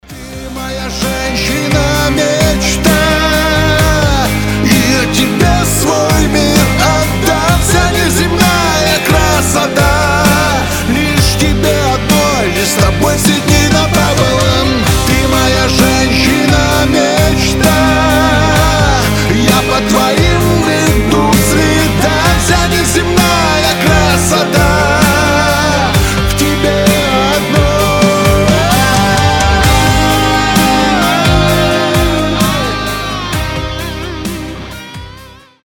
громкие